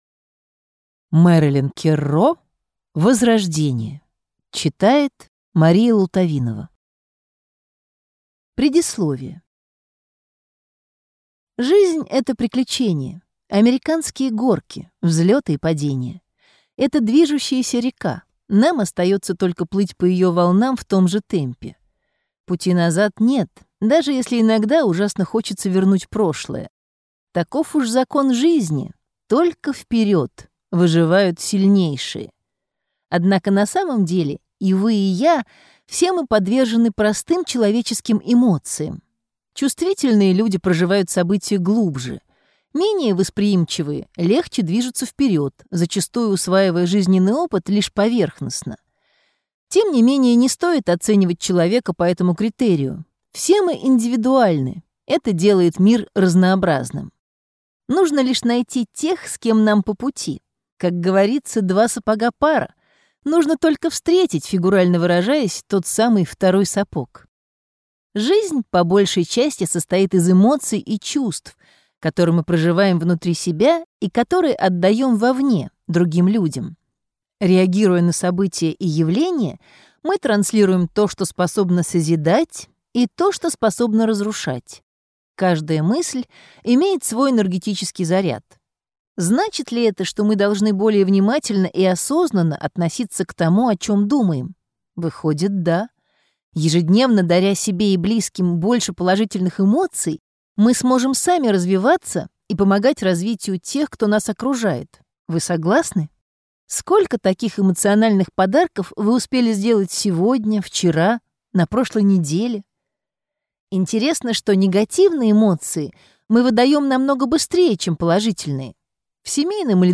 Аудиокнига Возрождение | Библиотека аудиокниг